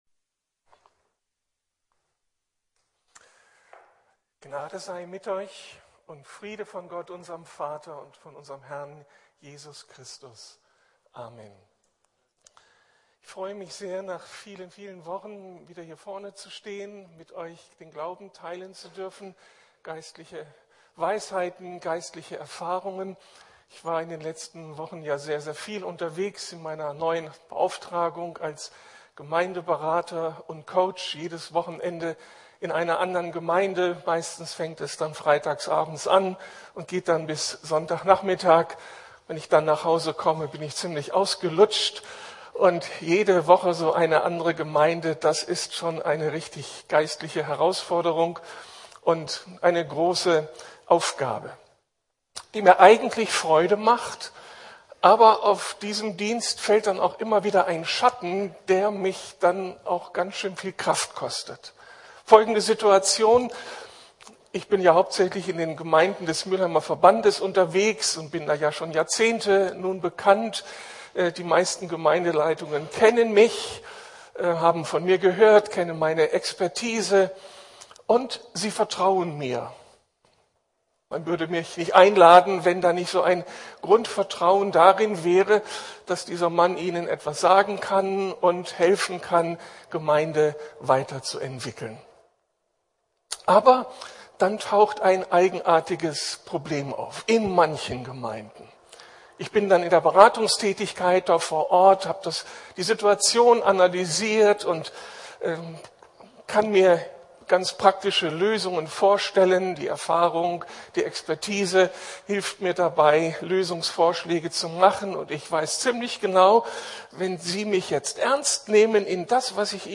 Proaktiv Glauben wagen ~ Predigten der LUKAS GEMEINDE Podcast